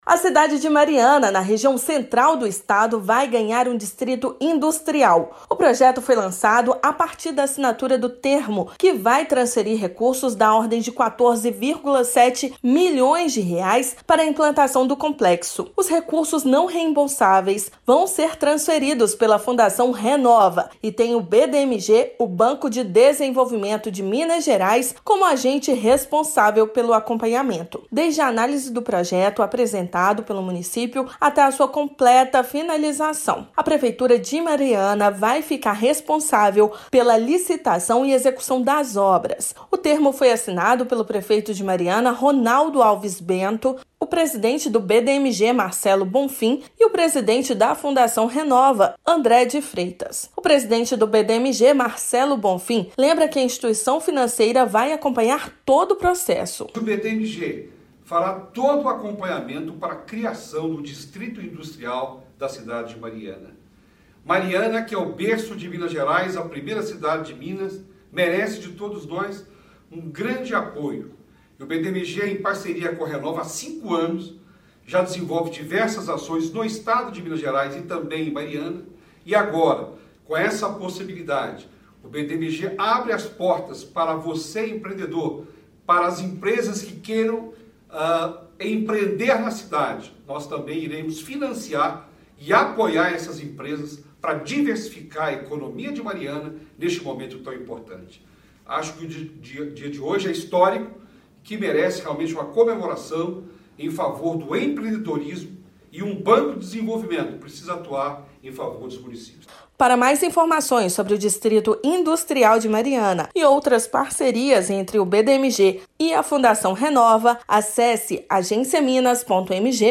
Parceria entre BDMG, Fundação Renova e município destina R$ 14,7 milhões para implantação do novo complexo. Ouça matéria de rádio.